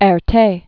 (ĕr-tā) Originally Romain de Tirtoff. 1892-1990.